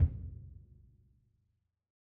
BDrumNewhit_v3_rr1_Sum.wav